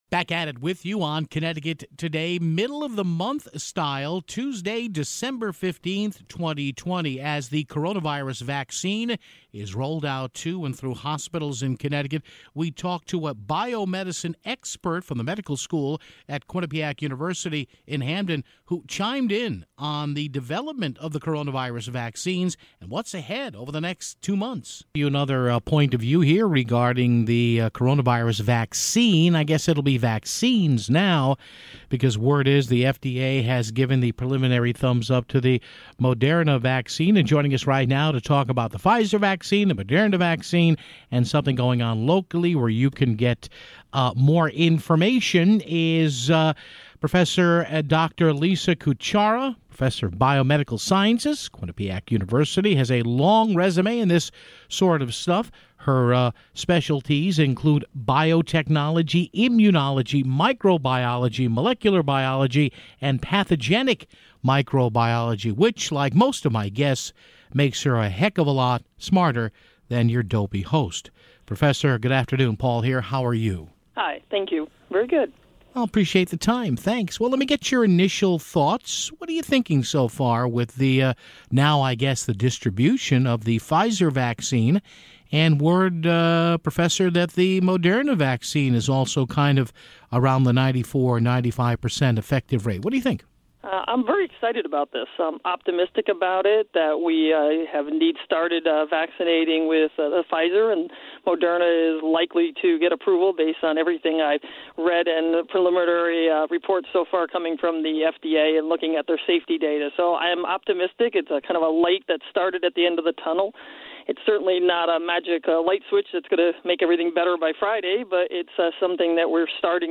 We wrapped up the show with portions of Governor Ned Lamont and company talking about the upcoming snow storm and latest with the vaccine (11:56)